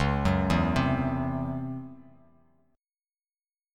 C#mM7bb5 chord